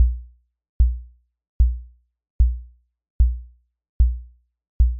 Free Horror sound effect: Heartbeat.
Heartbeat
Heartbeat is a free horror sound effect available for download in MP3 format.
045_heartbeat.mp3